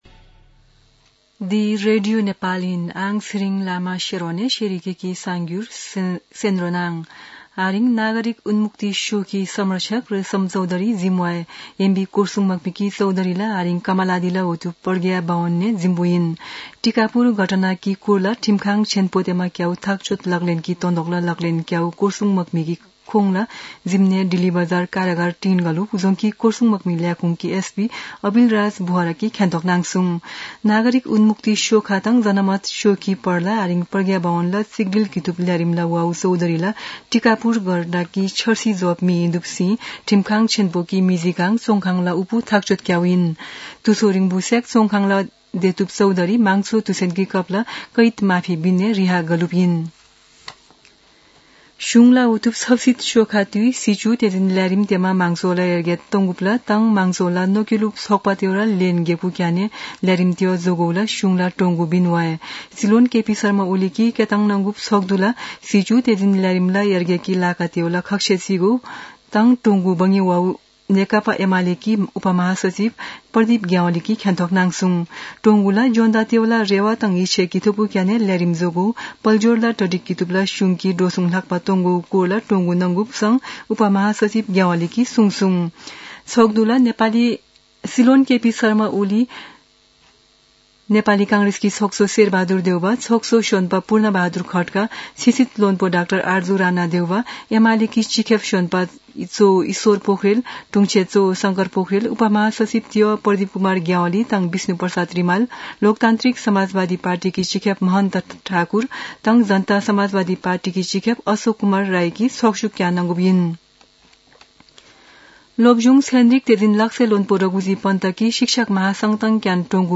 शेर्पा भाषाको समाचार : १७ वैशाख , २०८२
sharpa-news-3.mp3